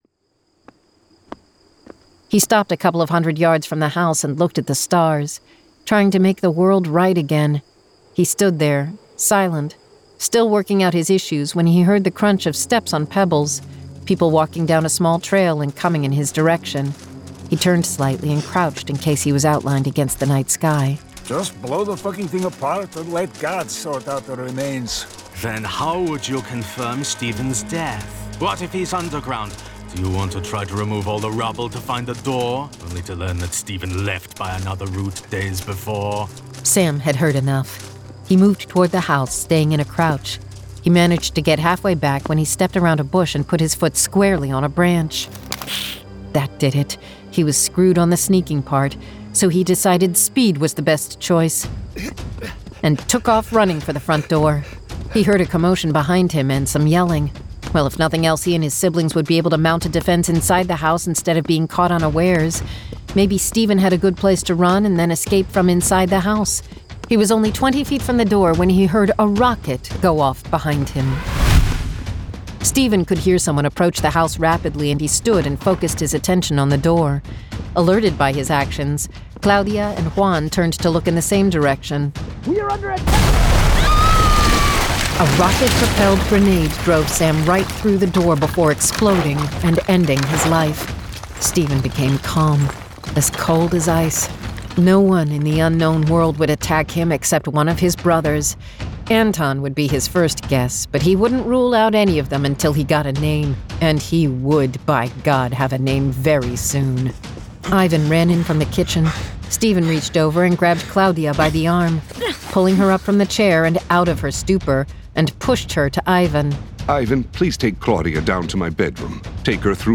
Full Cast. Cinematic Music. Sound Effects.
[Dramatized Adaptation]
Genre: Fantasy